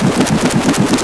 wipers2.wav